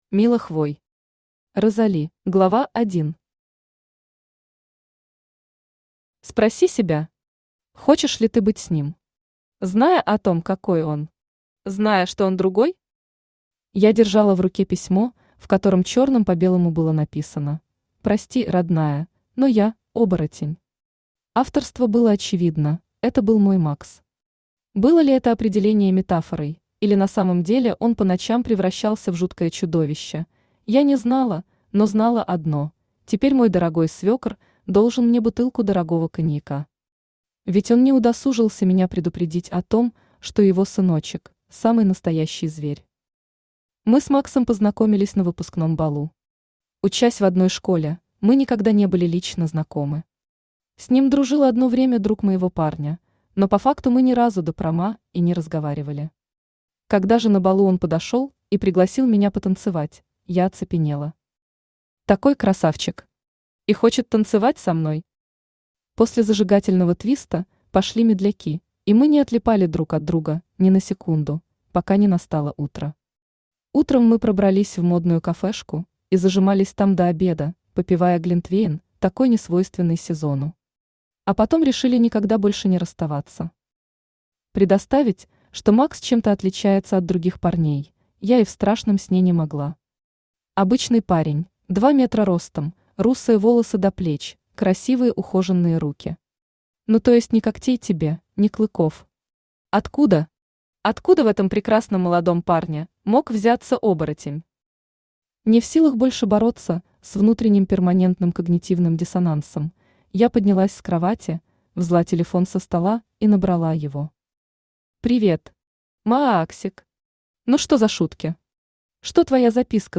Аудиокнига Розали | Библиотека аудиокниг
Aудиокнига Розали Автор Мила Хвой Читает аудиокнигу Авточтец ЛитРес.